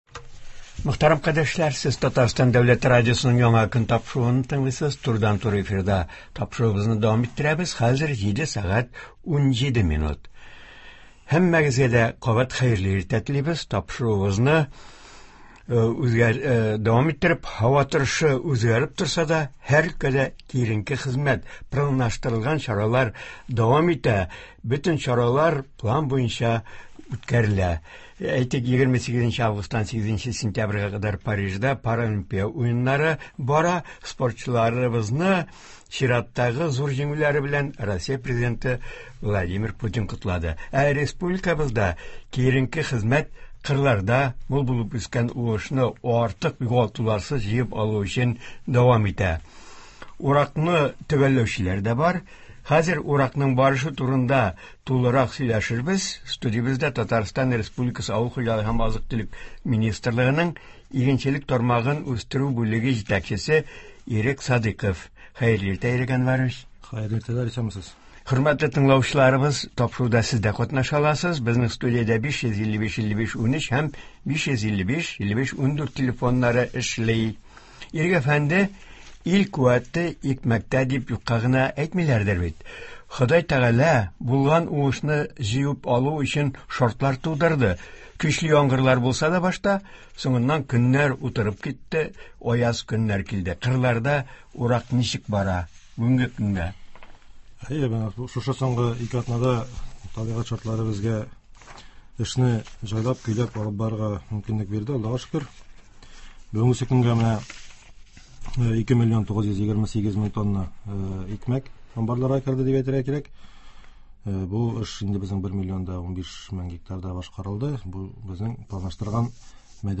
Республикабызда яңгырлар явудан туктагач, игенчелектә эш темпы артты. Күпчелек районнарда мул уңыш җыела. Болар хакында турыдан-туры эфирда